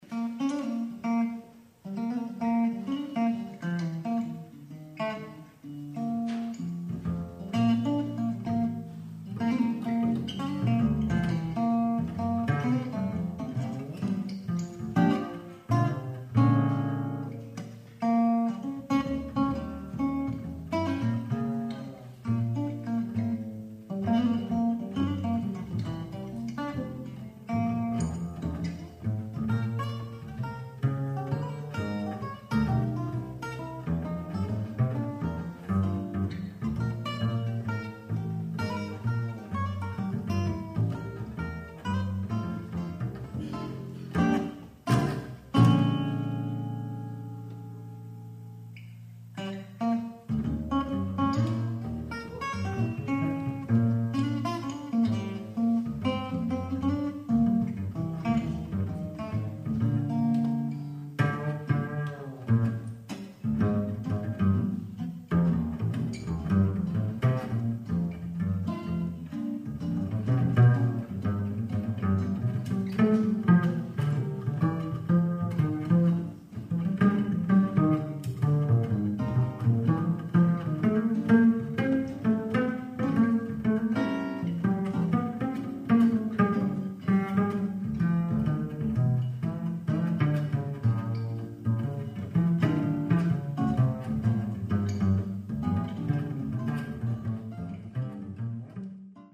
Guitar and Bass Duo Samples (mp3)
from a live concert